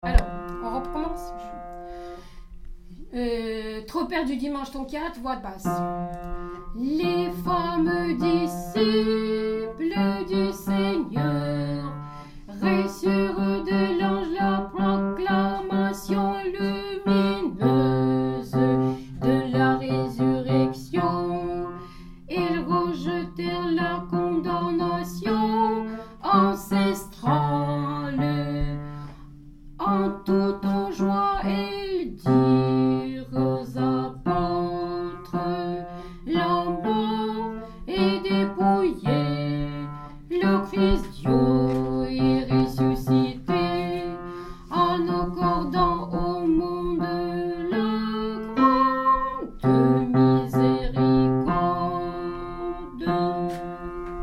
Stage de chant liturgique orthodoxe
Voix basse
Tropaire-ton4-Basse.MP3